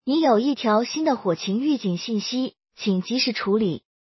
earlywarningaudio.mp3